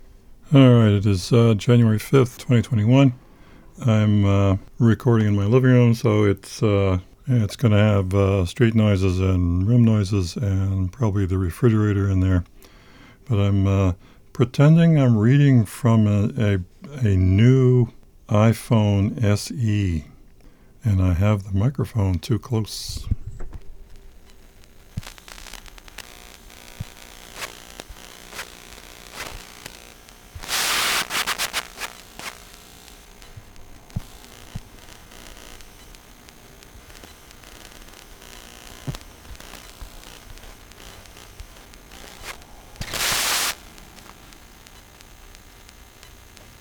On a whim, I pushed a microphone right in front of an iPhone screen.
Sounds a lot like your noises except yours are quieter.